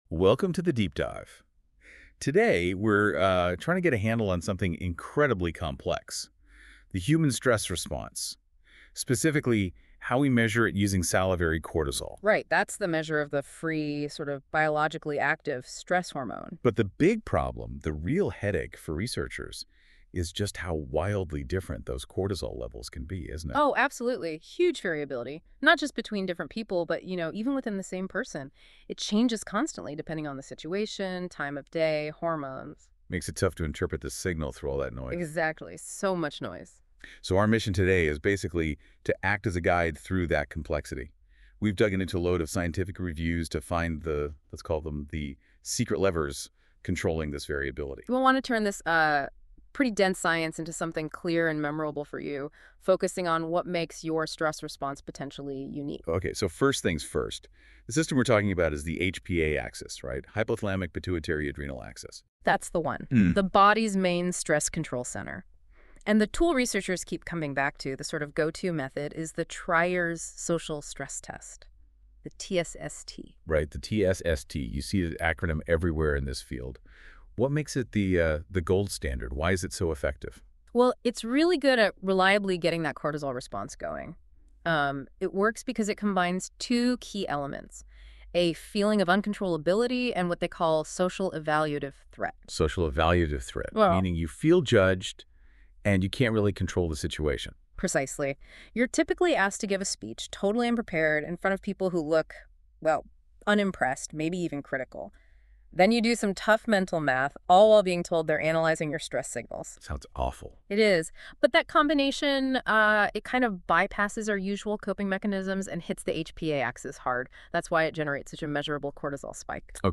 総説論文 Why do we respond so differently? Reviewing determinants of human salivary cortisol responses to challenge Author links open overlay panelBrigitte M. Kudielka a b , D.H. Hellhammer b, Stefan Wüst b c Psychoneuroendocrinology Volume 34, Issue 1, January 2009, Pages 2-18 Psychoneuroendocrinology を、Google NoteBookLMの音声概要作成機能を利用して、わかりやすく解説してもらいました。